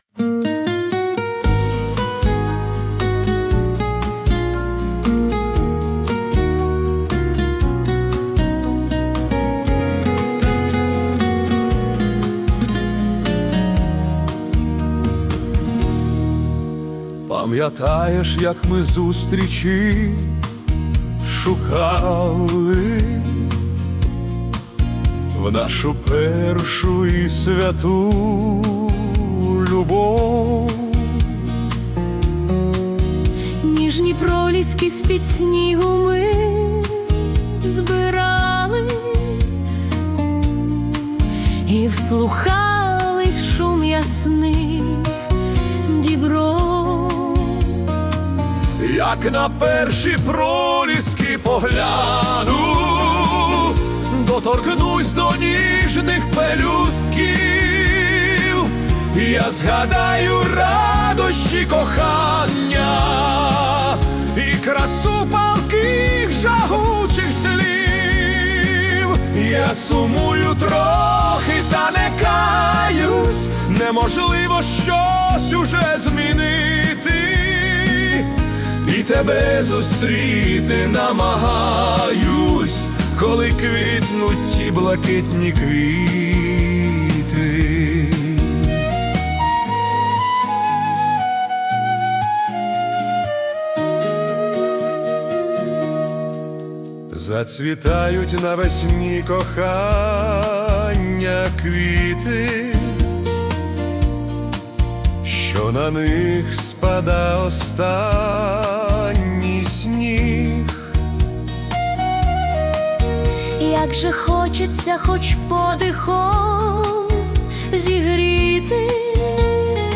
🎵 Жанр: Melodic Italo Disco